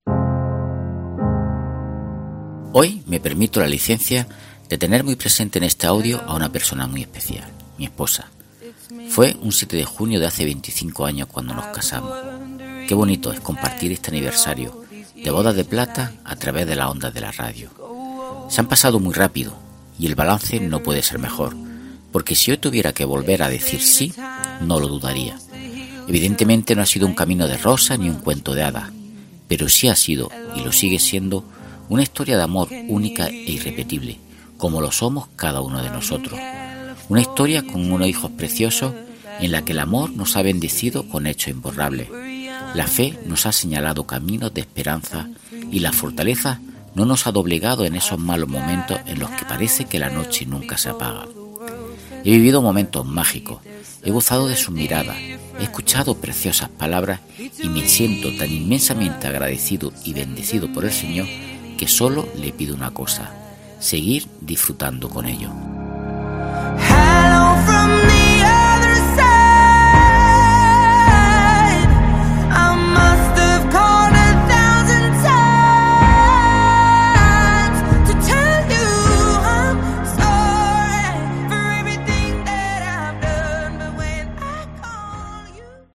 REFLEXIÓN